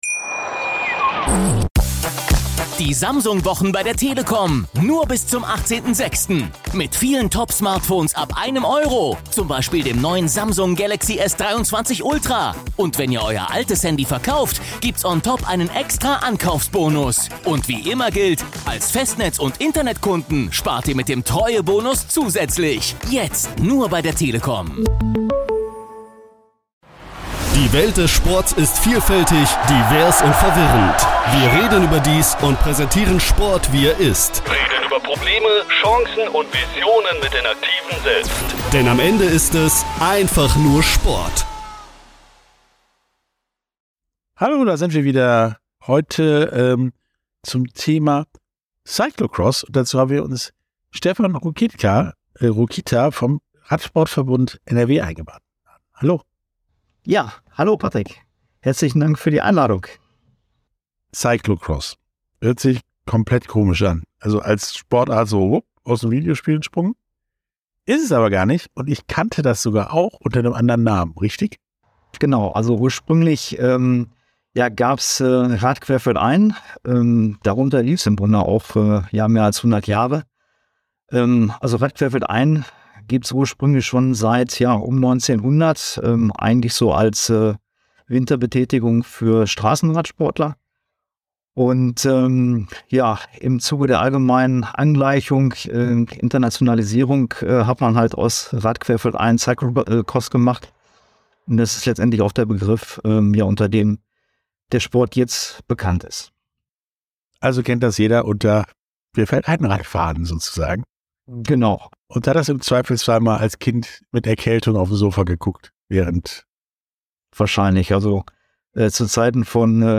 Podcast-Interview